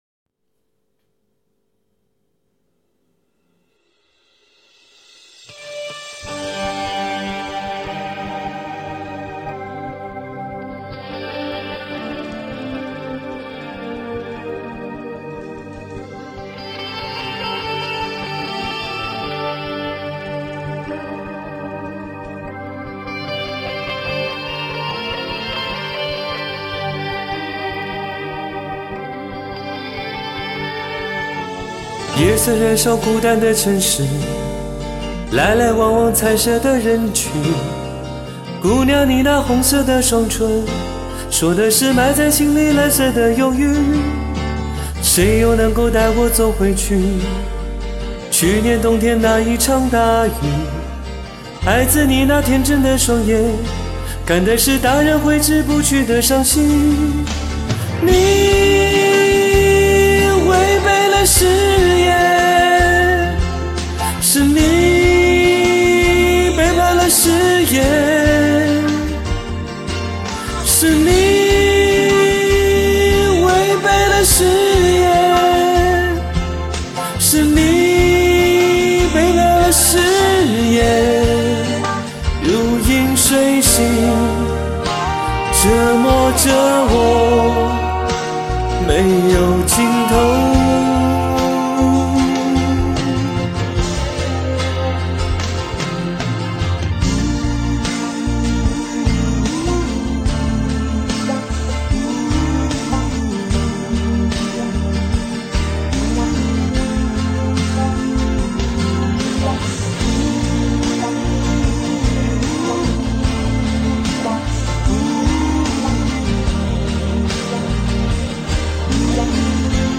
高音好像还更轻松些。
唱的非常好，很惊艳！
录音效果越来越好，和电脑没啥区别了。